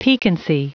Prononciation du mot piquancy en anglais (fichier audio)
Prononciation du mot : piquancy